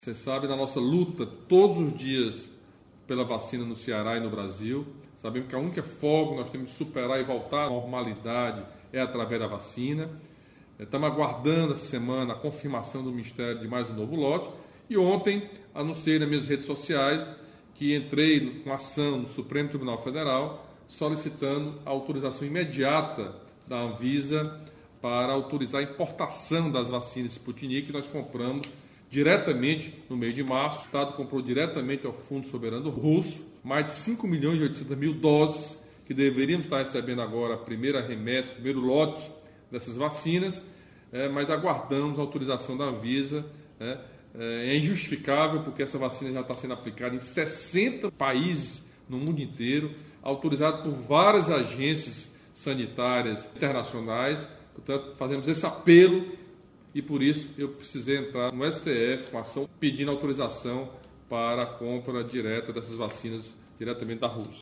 O governador Camilo Santana comentou a ampliação do benefício.
O governador Camilo Santana destacou a luta para ampliar a quantidade de vacinas no Ceará.